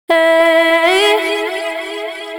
Soft Female House Vocal
soft-female-house-vocals-alley-echoing-melody_100bpm_E_major.wav